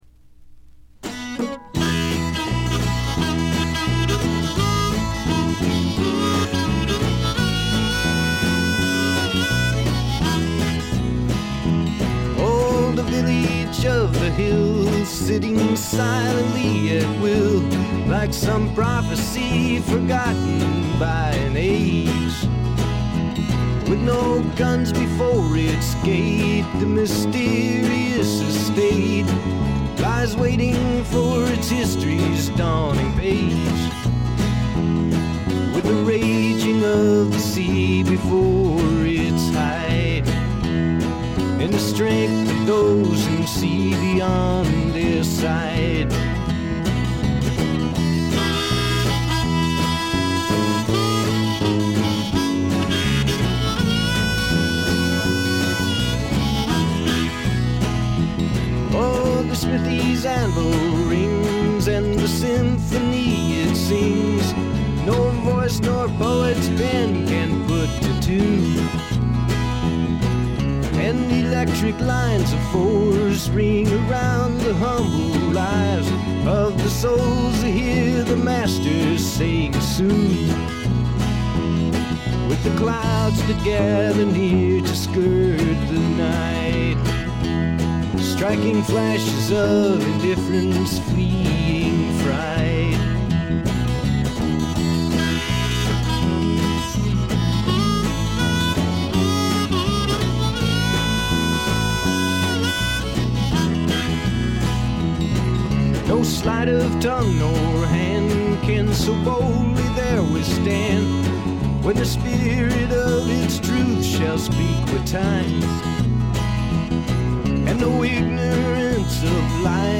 ほとんどノイズ感無し。
試聴曲は現品からの取り込み音源です。
Recorded at The Village Recorder